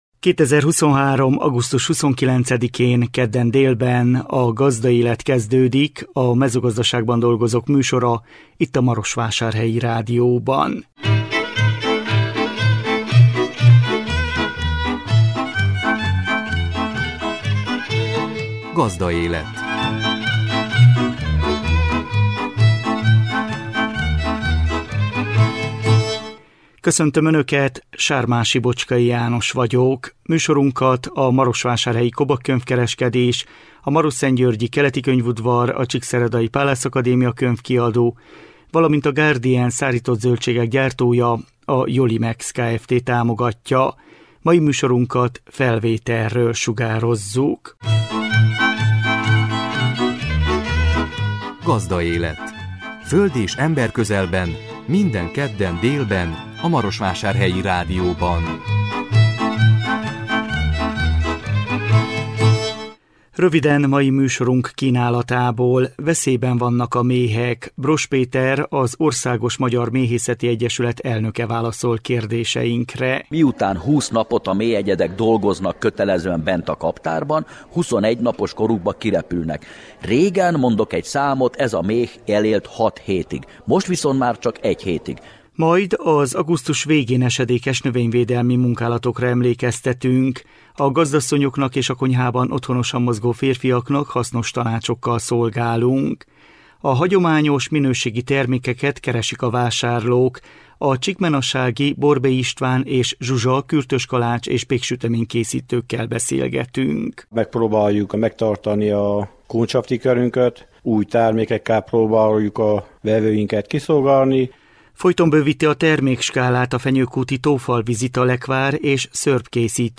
Vele készült interjúnk.